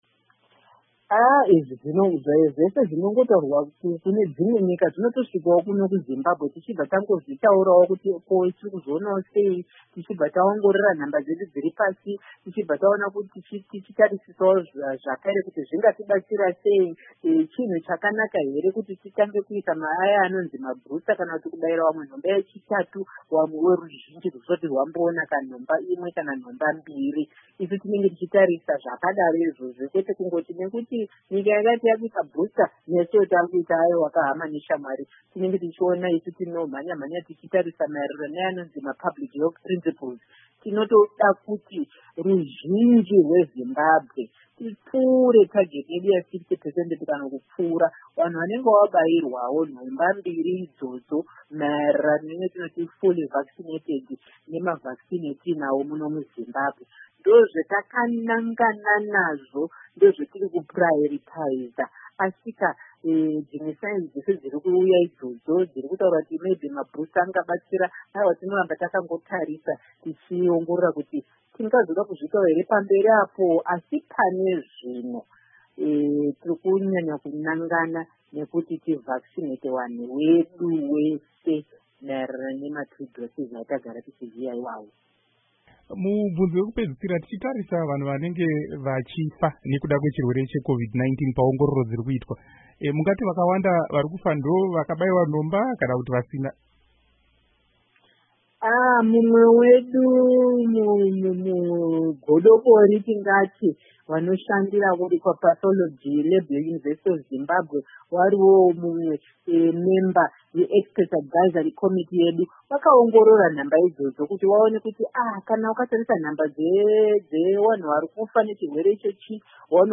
Hurukuro naDr Agnes Mahomva